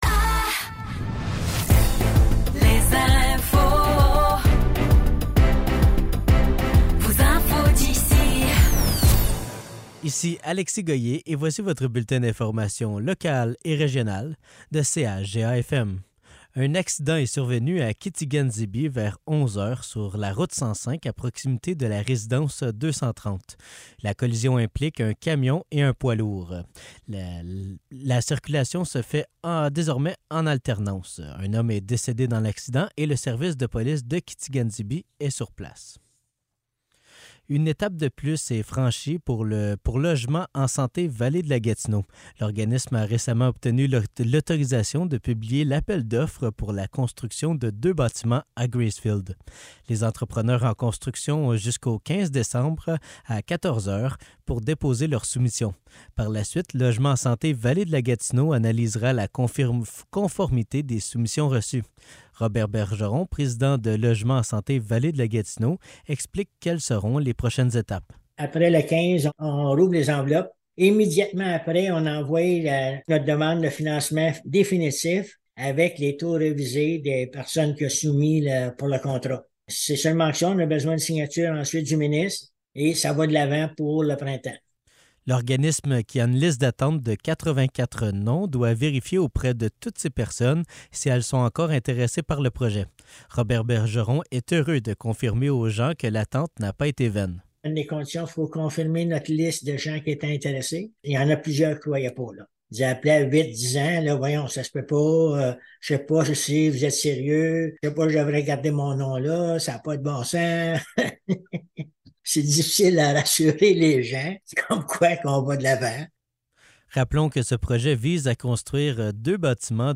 Nouvelles locales - 9 novembre 2023 - 15 h